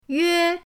yue1.mp3